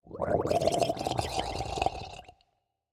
Minecraft Version Minecraft Version latest Latest Release | Latest Snapshot latest / assets / minecraft / sounds / mob / drowned / water / idle4.ogg Compare With Compare With Latest Release | Latest Snapshot